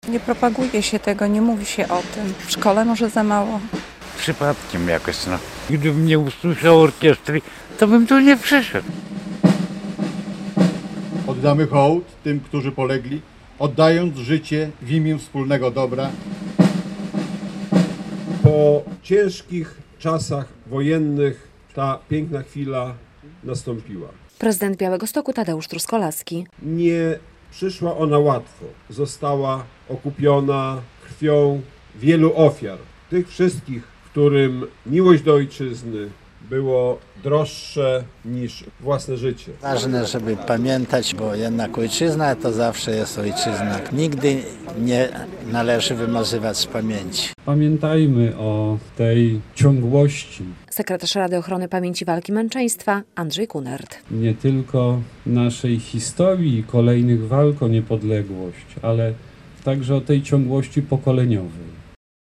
Były przemówienia, występ orkiestry i kwiaty. Przedstawiciele władz miejskich i wojewódzkich, reprezentanci służb mundurowych, kombatanci i młodzież w pocztach sztandarowych uczcili 94. rocznicę wyzwolenia Białegostoku.